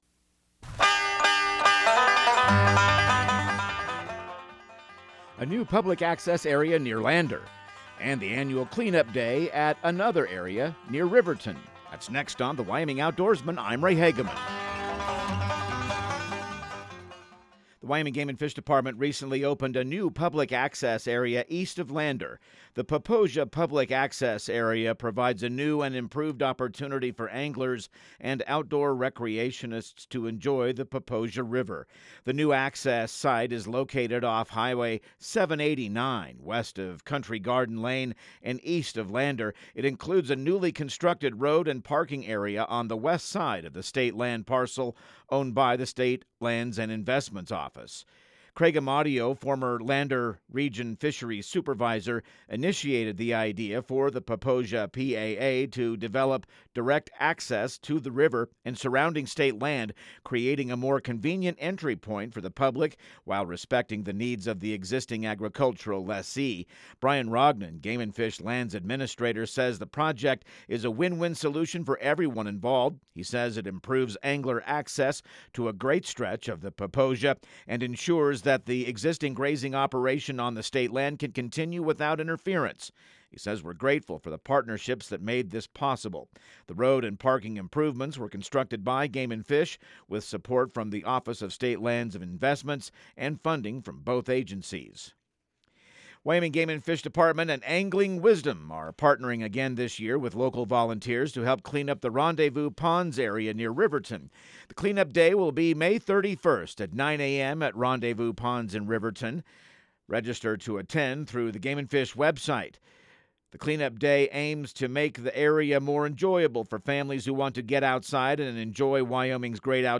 Radio news | Week of May 26